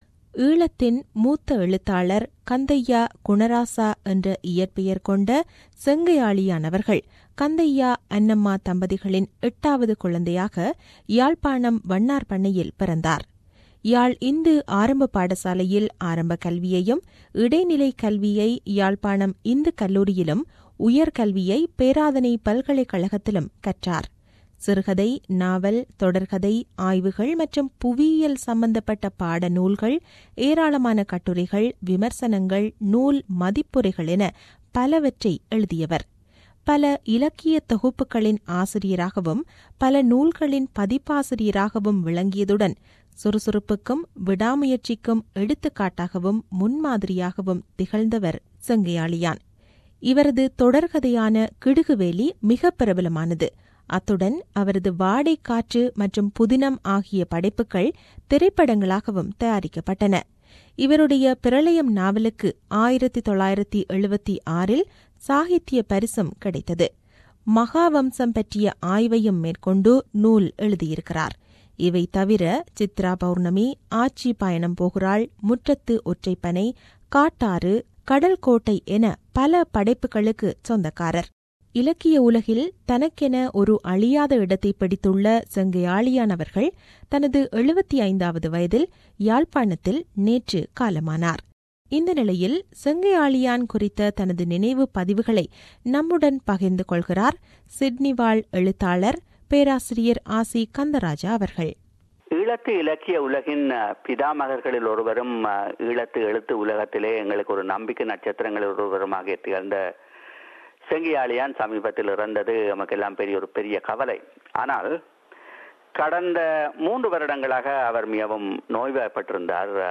SBS Tamil presents a eulogy on Mr. Sengai Aaliyan 75, who passed away on Sunday.